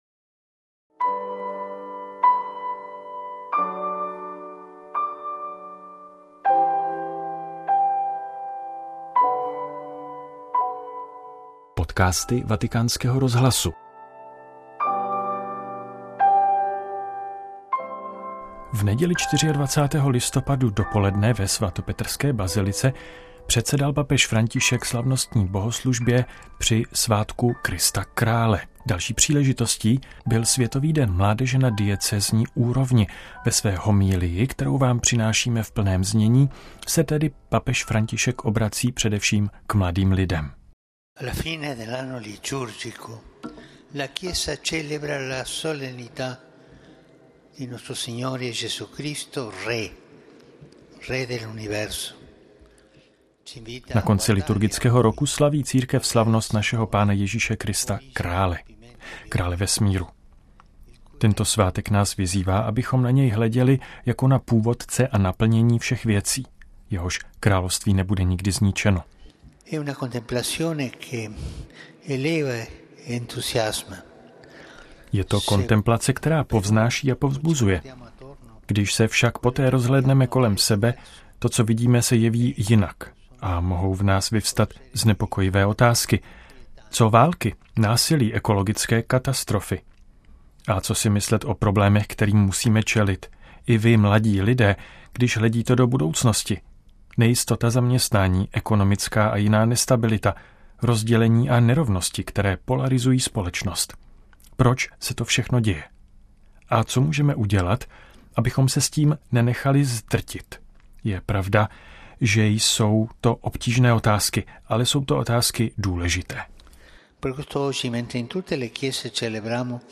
Papežova homilie na slavnost Krista Krále - 24.11.2024